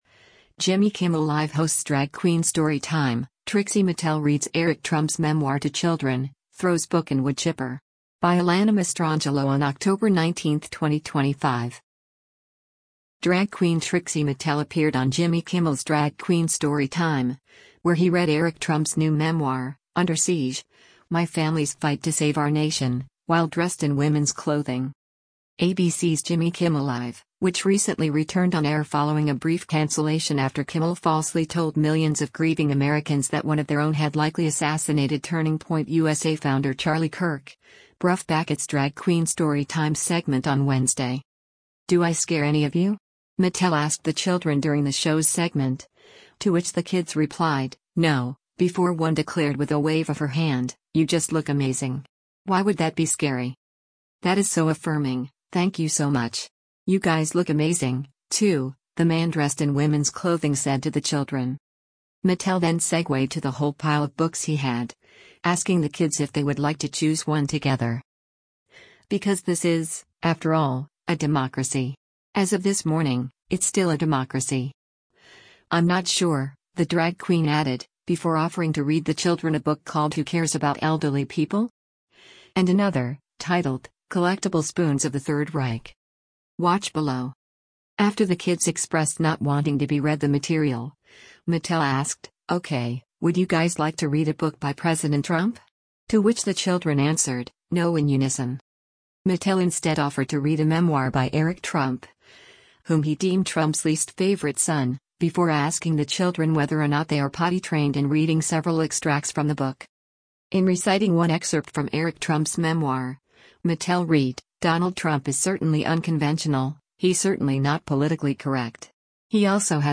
Drag queen Trixie Mattel appeared on Jimmy Kimmel’s Drag Queen Storytime, where he read Eric Trump’s new memoir, Under Siege: My Family’s Fight to Save Our Nation, while dressed in women’s clothing.
After the kids expressed not wanting to be read the material, Mattel asked, “Okay, would you guys like to read a book by President Trump?” to which the children answered, “No” in unison.
Mattel then brings the kids outside for what he called a “special science experiment,” which involved throwing a copy of the book into a wood chipper.